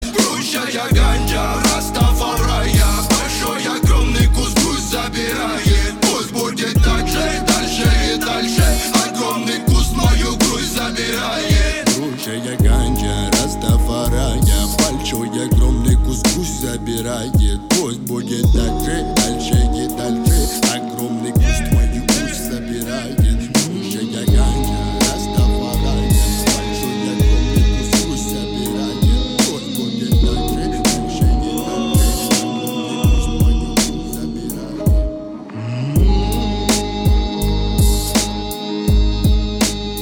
• Качество: 320, Stereo
русский рэп
качающие